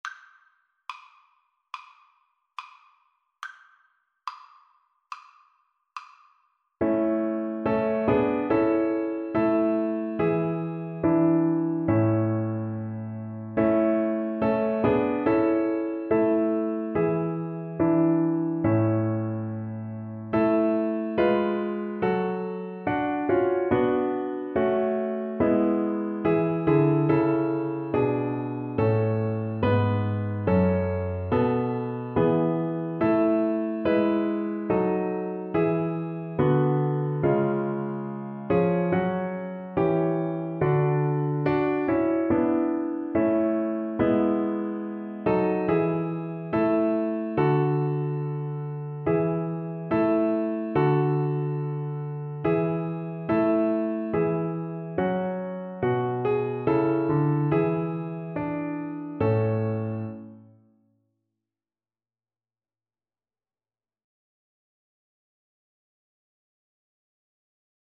Play (or use space bar on your keyboard) Pause Music Playalong - Piano Accompaniment Playalong Band Accompaniment not yet available transpose reset tempo print settings full screen
Violin
4/4 (View more 4/4 Music)
Moderato
A major (Sounding Pitch) (View more A major Music for Violin )
Traditional (View more Traditional Violin Music)